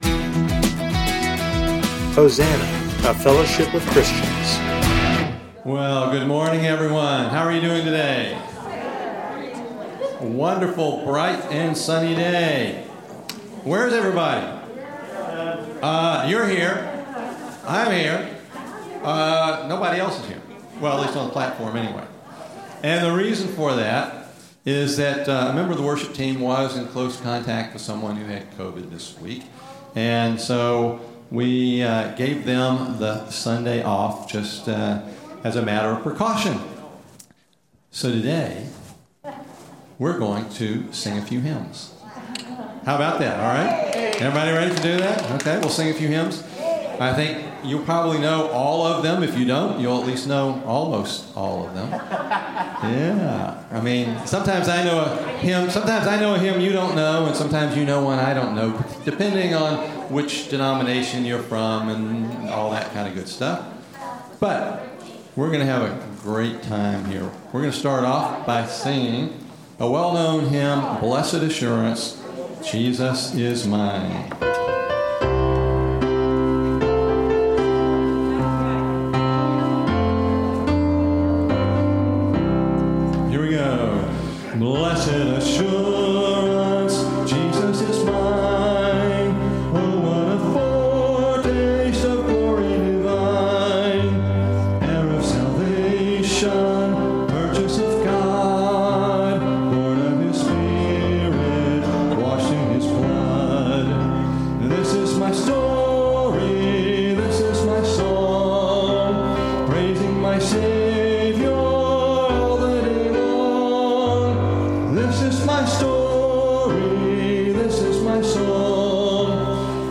Genre: Spoken Word.